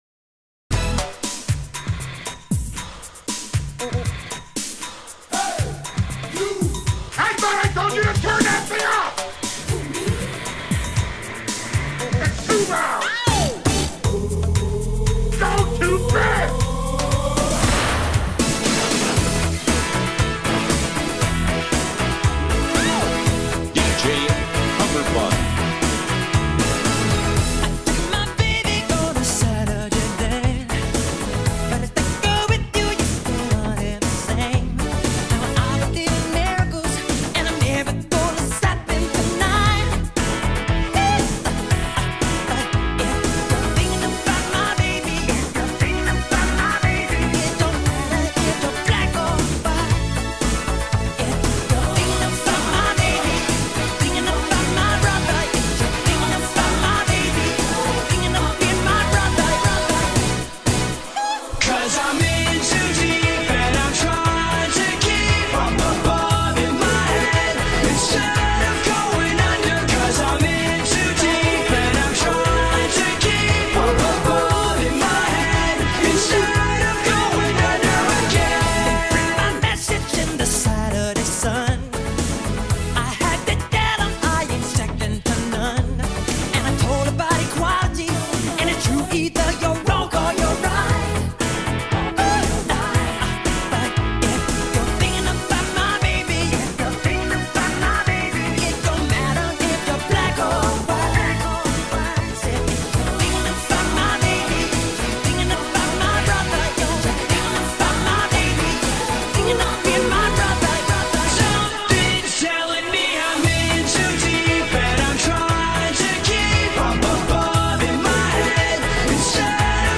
Mashup